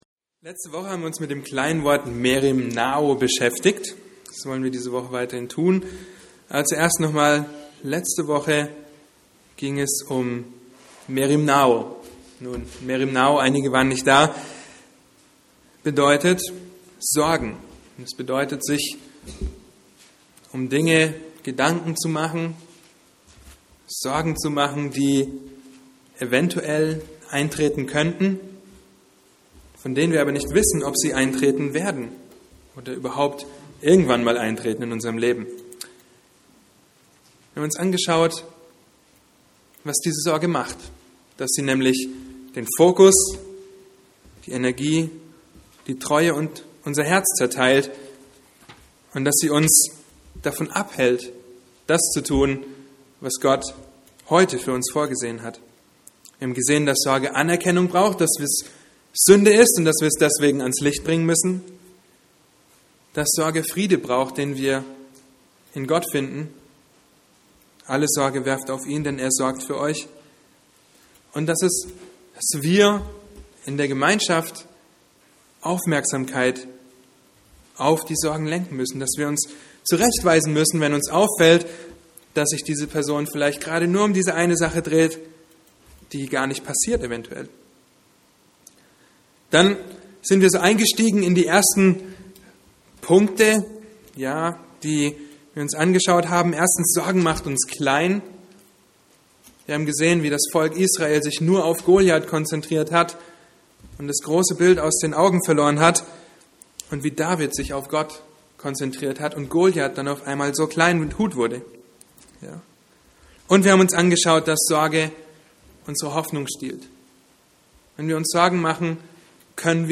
A predigt from the serie "Weitere Predigten." Deine Auffassung von der Weihnachtsbotschaft der Bibel ist von größter Bedeutung